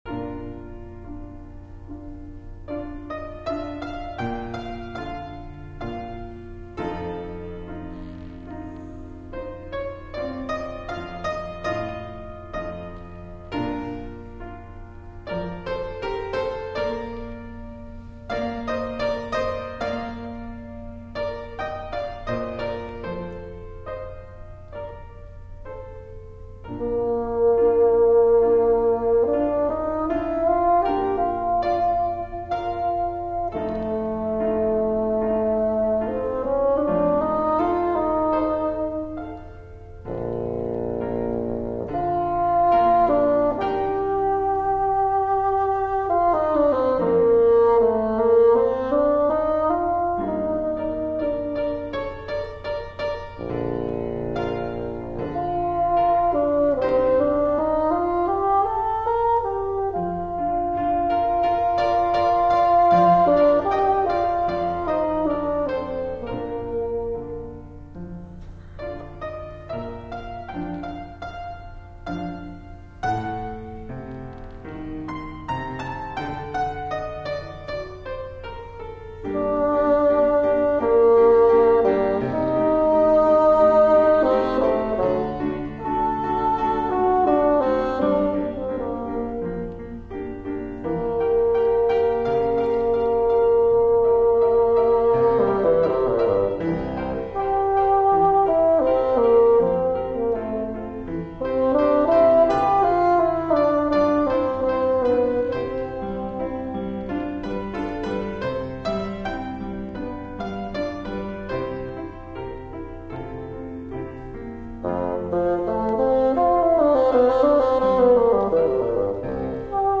bassoon
fortepiano Oct. 1997 Kent University